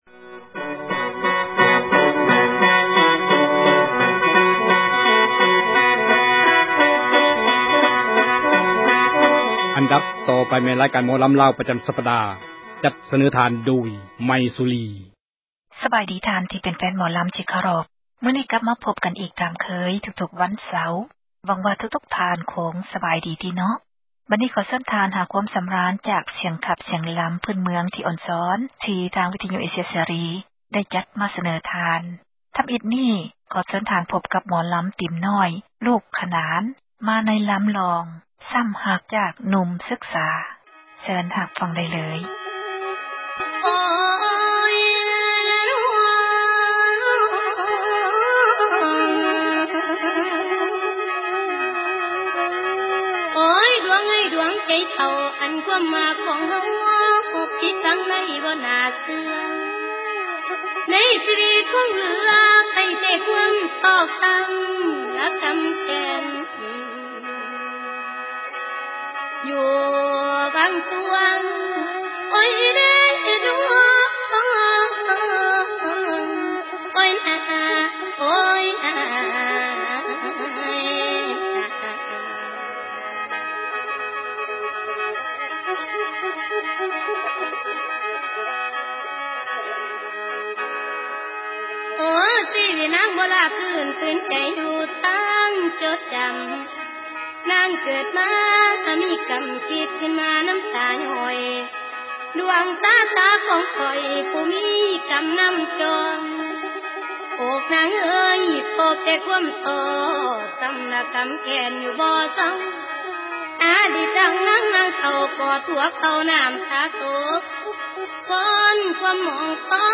ຣາຍການໝໍລໍາລາວ ປະຈໍາສັປດາ.